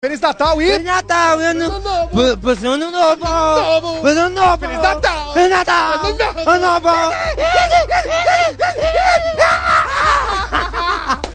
Dois homens humoristas se desejam mutuamente Feliz Natal e Feliz Ano Novo.